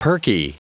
Prononciation du mot perky en anglais (fichier audio)
Prononciation du mot : perky